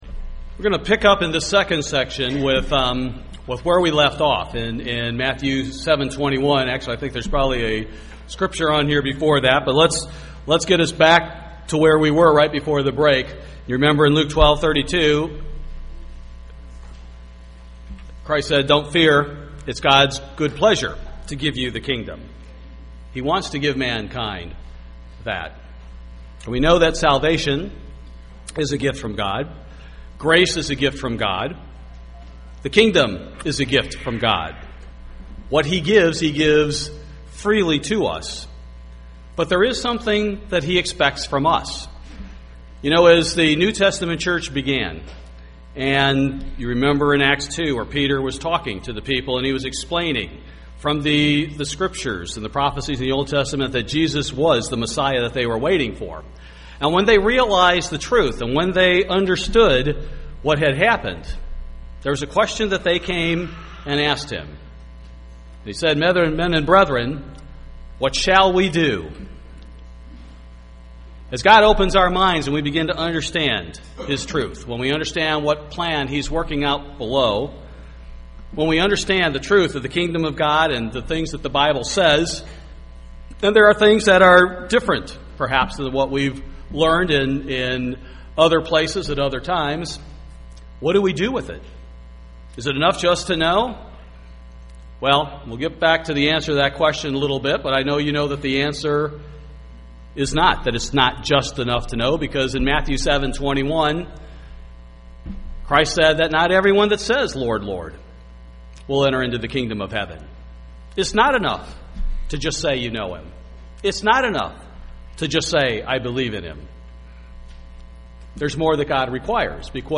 Those who witnessed the miracle at Pentecost were told to repent and believe the gospel, and we are to do likewise. Learn more in this Kingdom of God seminar.